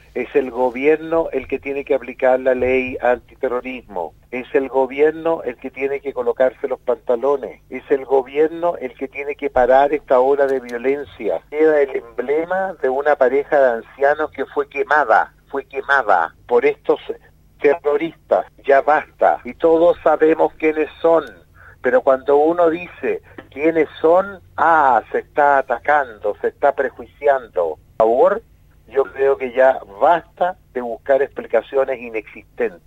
En diálogo con Radio Sago el Senador Moreira fue enfático en señalar que éste y los anteriores atentados a maquinaria han sido hechos terroristas y no aislados como el Gobierno les ha denominado.